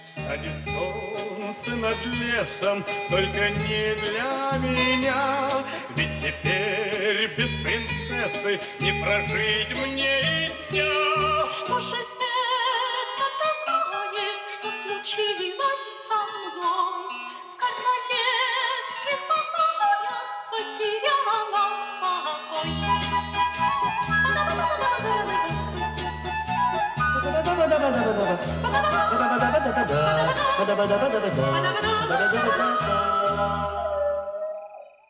• Качество: 320, Stereo
добрые